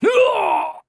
sound / monster / chuhen / dead_1.wav
dead_1.wav